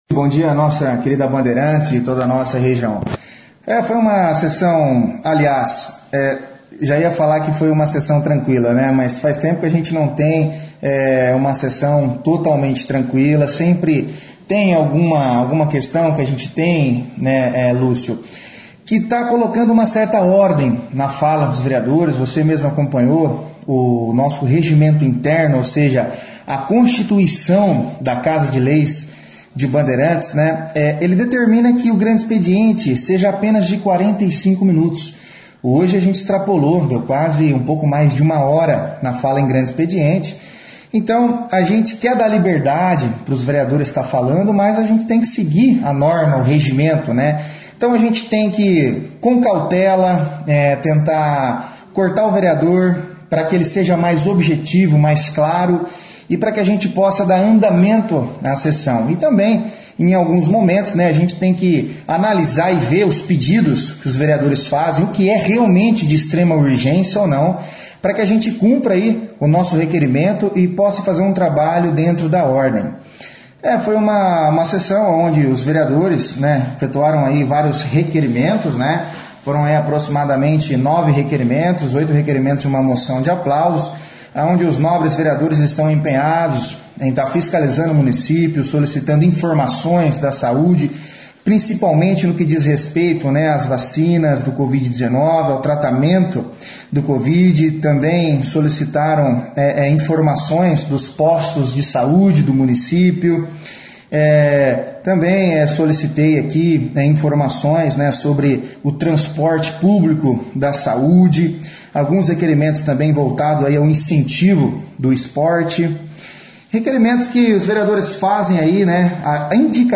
A sessão foi destaque na 2ª edição do jornal Operação Cidade desta quarta-feira, 26/05, com a participação do presidente da casa vereador Mano Vieira, que falou sobre a sessão.